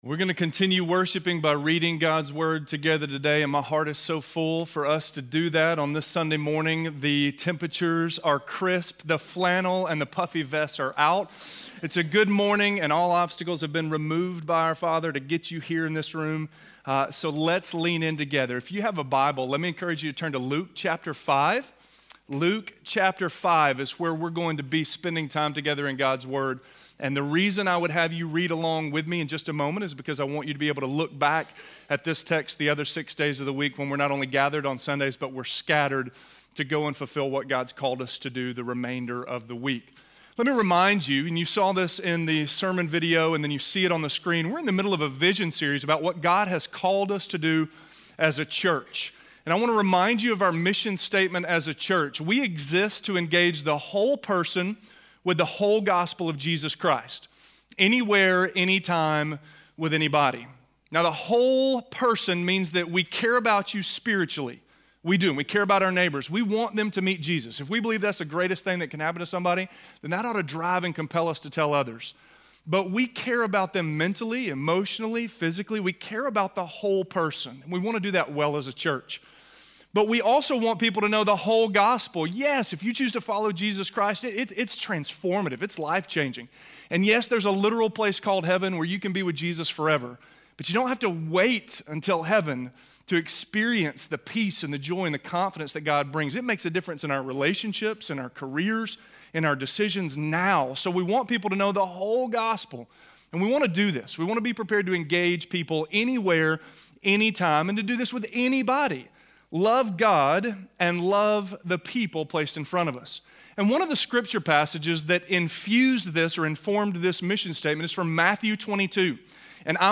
Loving God With All of My Heart - Sermon - Avenue South